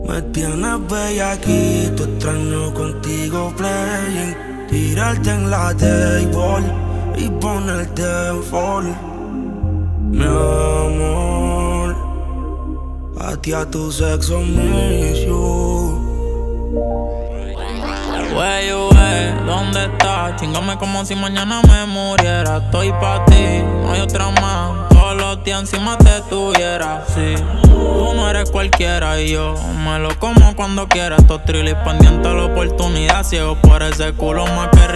# Latin Urban